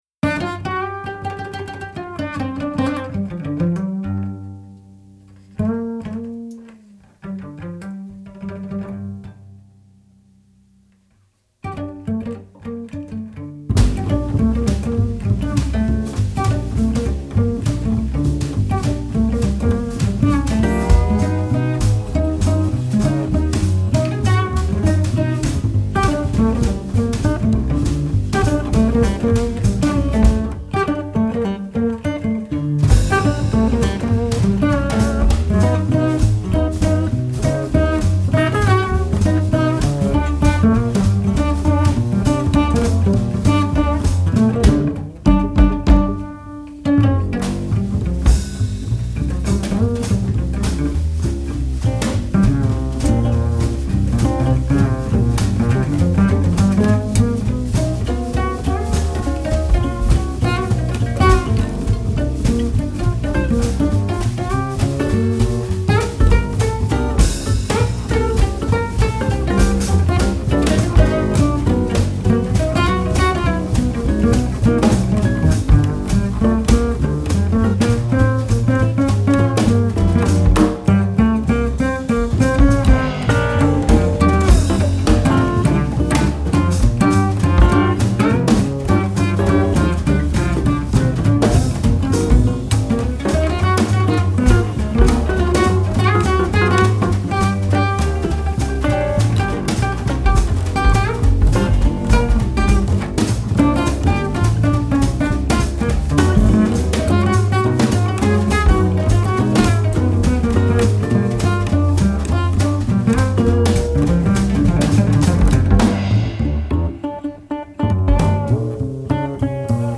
cello
guitar
drums
bass
Southern rock
slide-guitar player